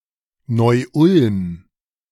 Neu-Ulm (German pronunciation: [nɔʏˈʔʊlm]